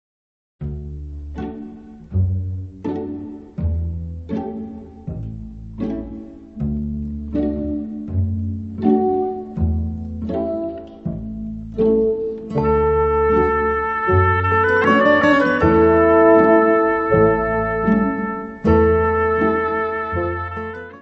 Music Category/Genre:  Soundtracks, Anthems, and others